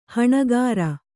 ♪ haṇagāra